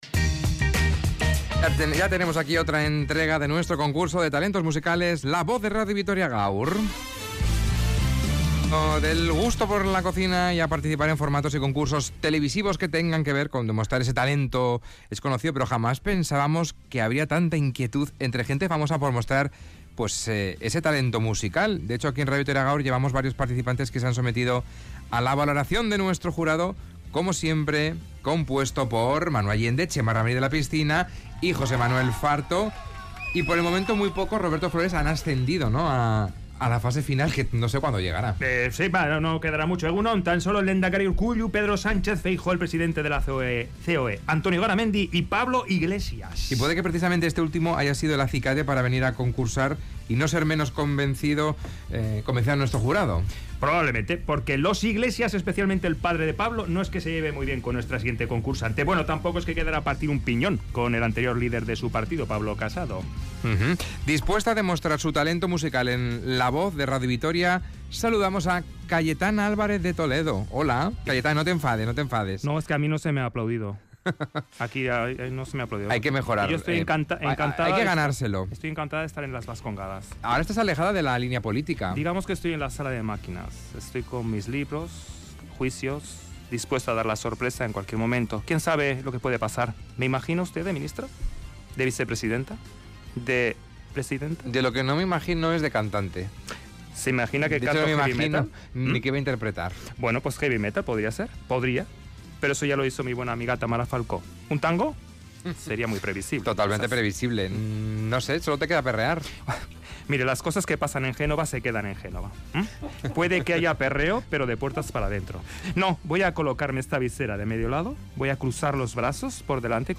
Audio: Su objetivo es pasar a la fase final del concurso de talentos de Radio Vitoria.